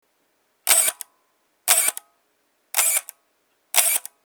まずはこのシャッター音を。
ついでに１／５００だと・・
robot1_500.mp3